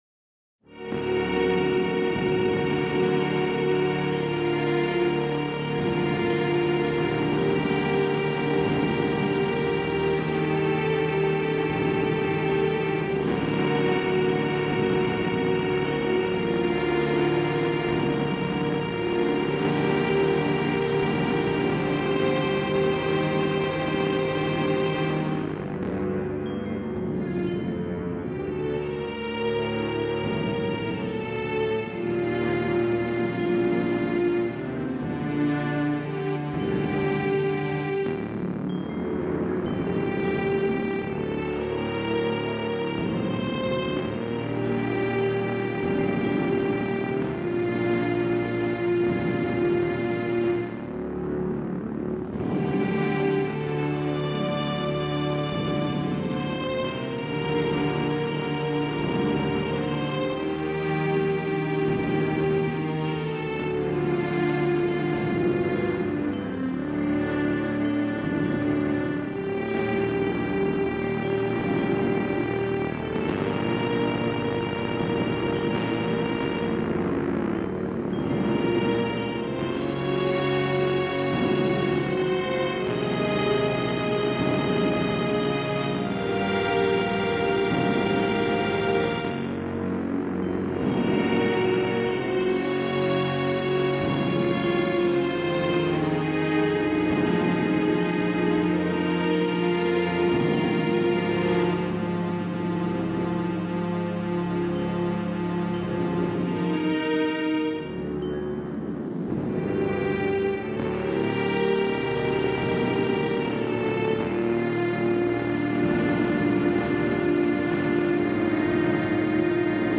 Tarlite Swamplands Entanglement Area 3 BGM Music for Video Game Murky Style